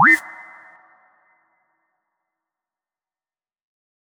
MENU B_Select.wav